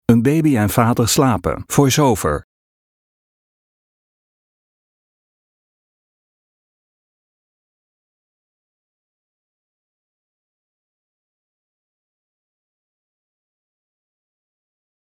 Gesnurk